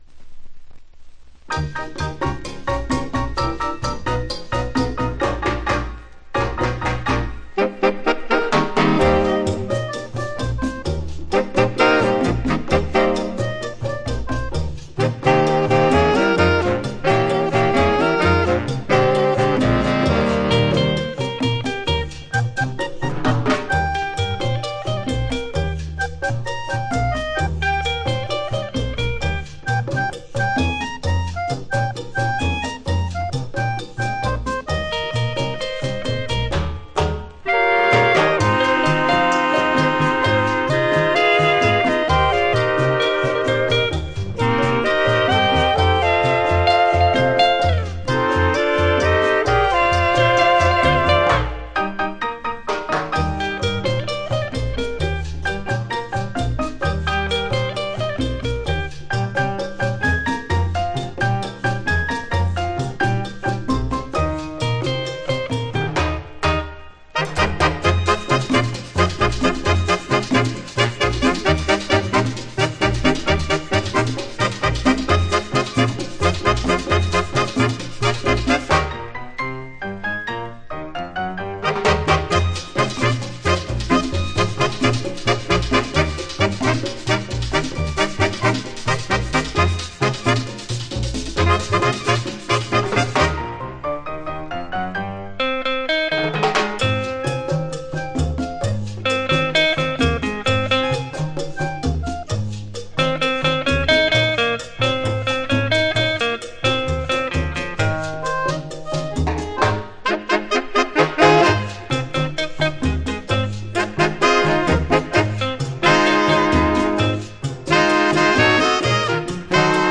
Жанр: Pop, Latin, Easy Listening